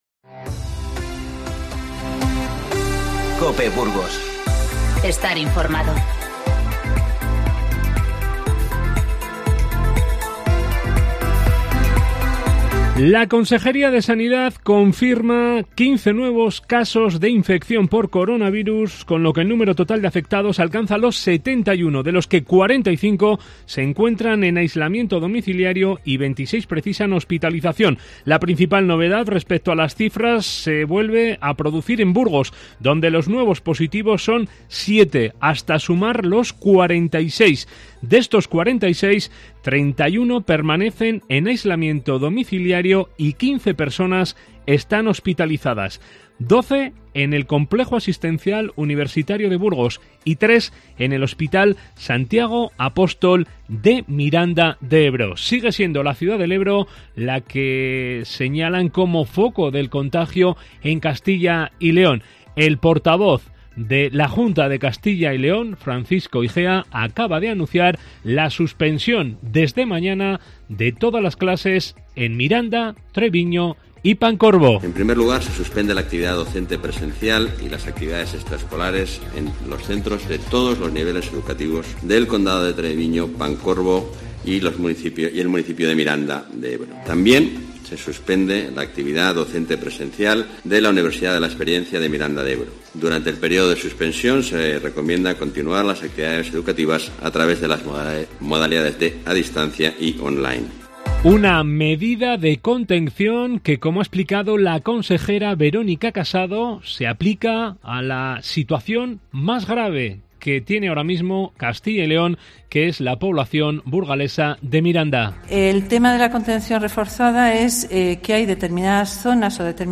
Informativo 11-03-20